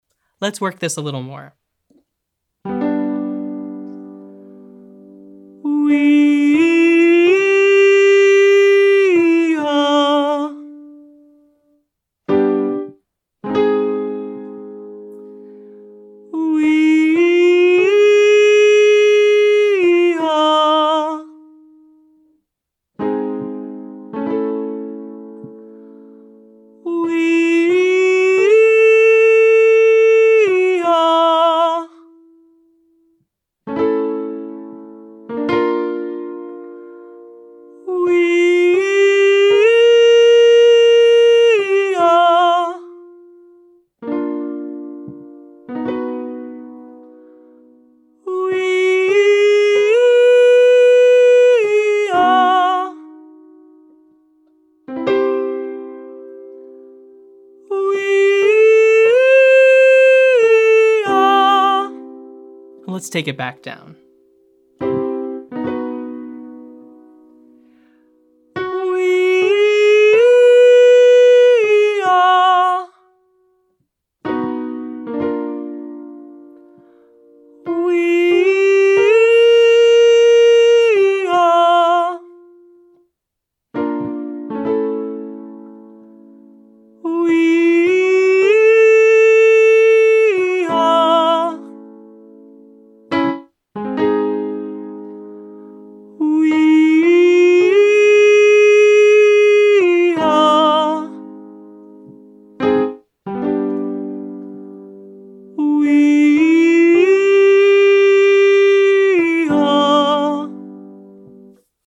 Exercise 2:  WEE/OO to UH 56-865 head down
First we start out in head voice and sustain here for a moment.